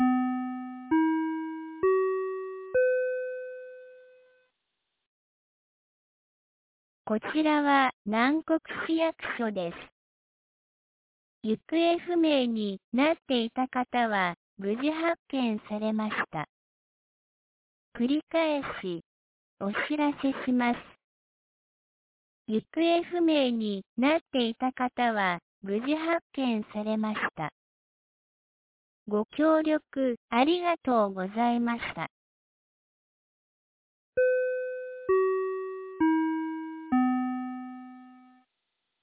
2025年09月19日 11時05分に、南国市より放送がありました。